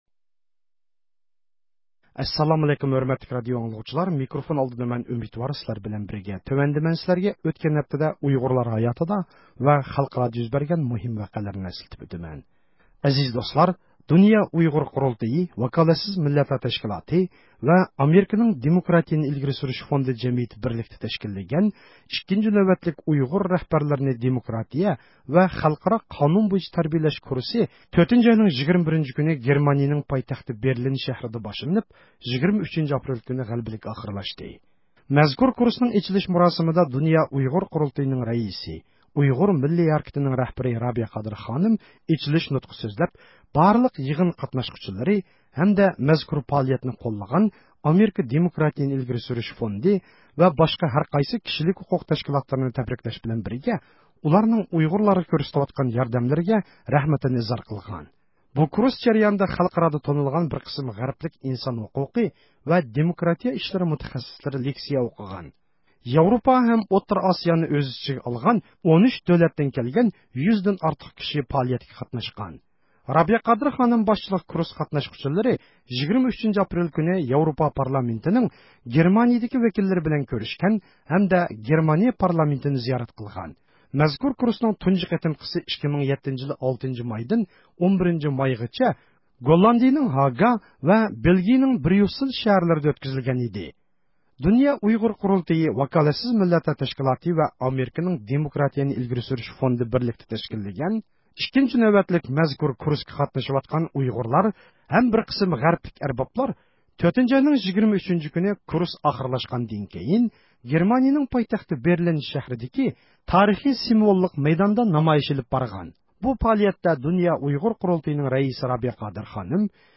ھەپتىلىك خەۋەرلەر (19 – ئاپرېلدىن 25 – ئاپرېلغىچە) – ئۇيغۇر مىللى ھەركىتى